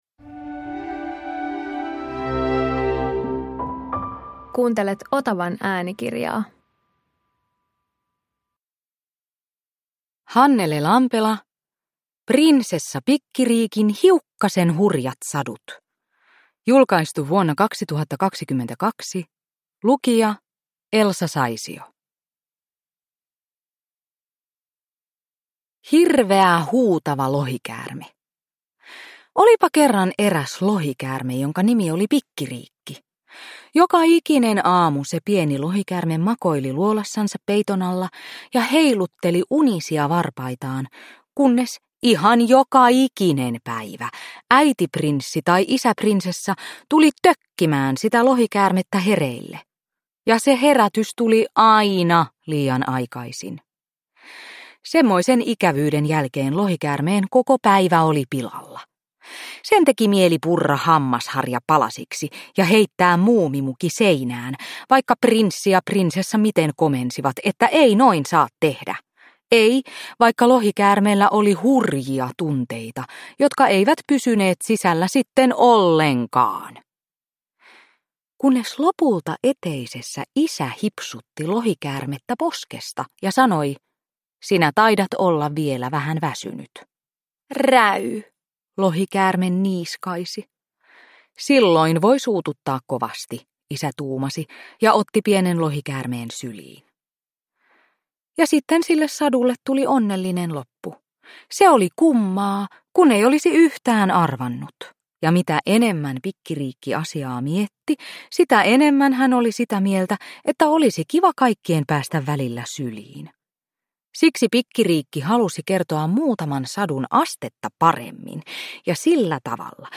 Prinsessa Pikkiriikin hiukkasen hurjat sadut – Ljudbok – Laddas ner